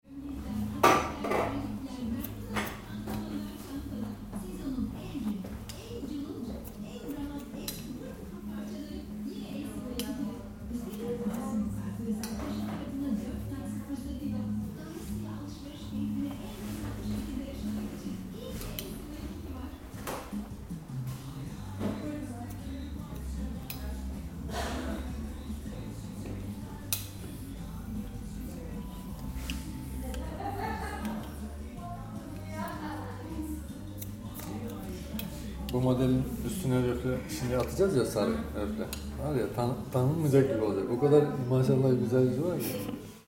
The sound of scissors clipping my hair was something that maked me feel just a bit pampered.